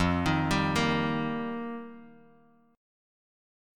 F7sus2sus4 chord